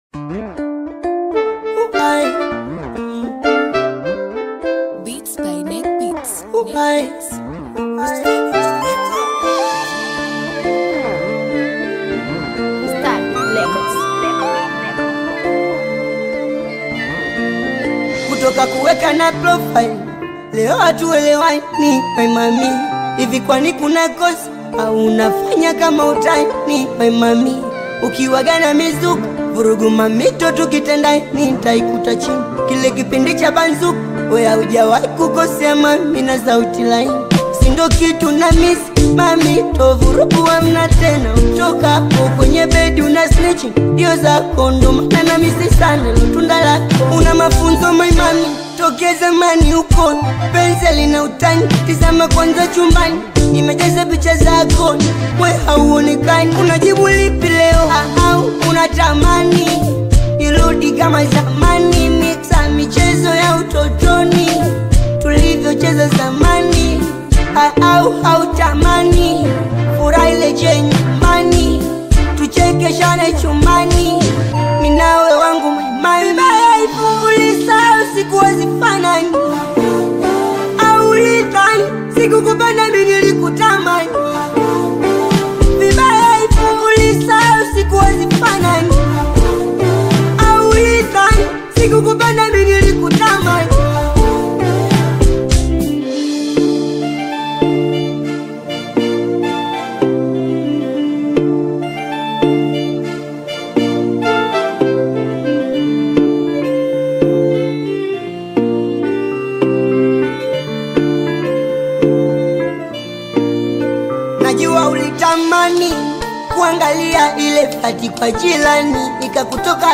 AudioBongo fleva
reflective Bongo Flava single